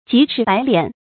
急赤白臉 注音： ㄐㄧˊ ㄔㄧˋ ㄅㄞˊ ㄌㄧㄢˇ 讀音讀法： 意思解釋： 心里著急，臉色難看。